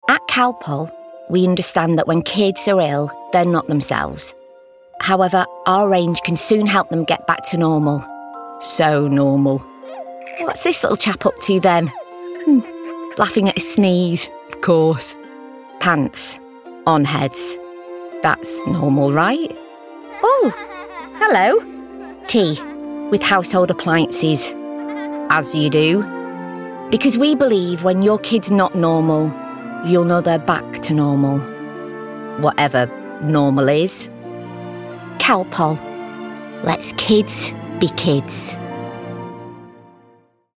***NEW ARTIST*** | 50s | Warm, Quirky & Natural
Voice reel